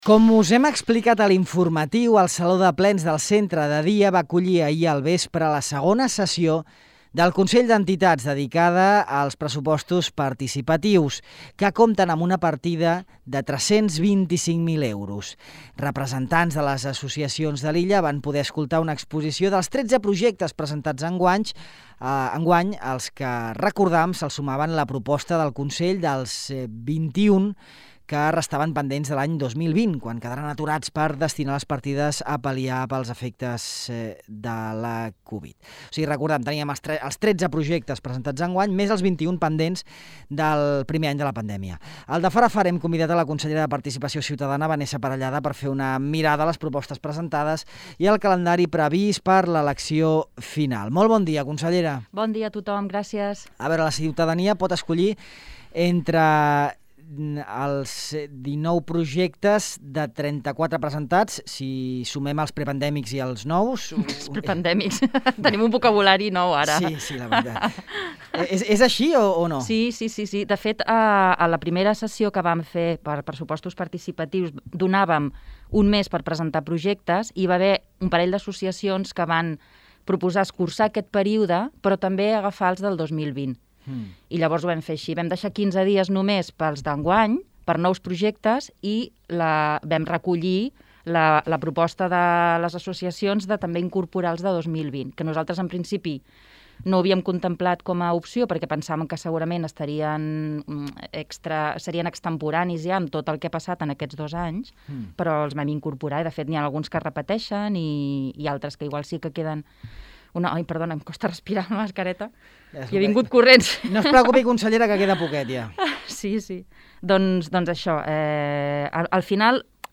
La consellera de Participació Ciutadana, Vanessa Parellada, explica en aquesta entrevista a Ràdio Illa que en la sessió d’ahir al vespre del Consell d’Entitats dedicada als Pressupostos Participatius 2022 es varen presentar trenta-cinc projectes, tretze dels quals són d’enguany i vint-i-dos s’han recuperat dels que havien quedat aturats el 2020, a causa de la covid.